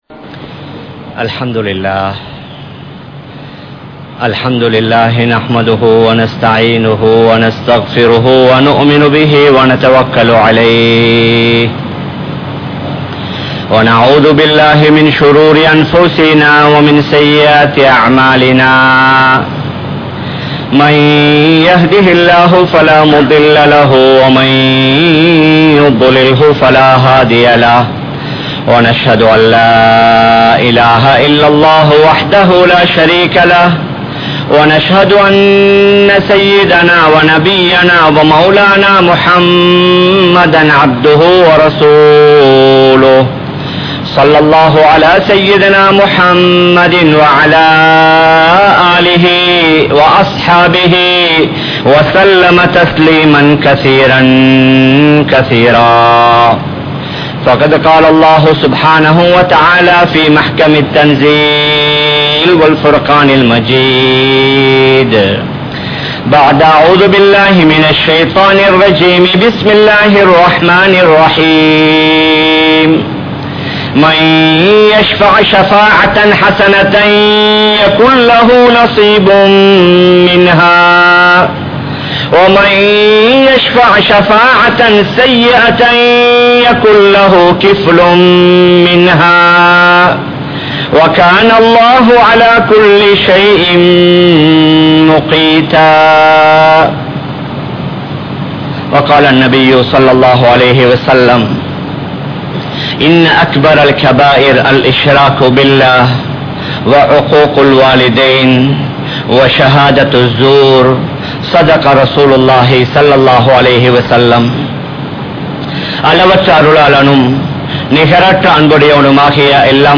Election (தேர்தல்) | Audio Bayans | All Ceylon Muslim Youth Community | Addalaichenai
Kollupitty Jumua Masjith